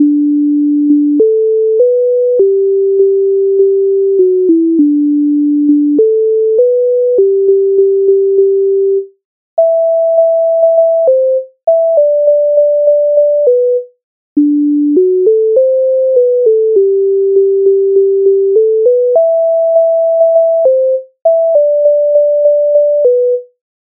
Українська народна пісня стрілецька пісня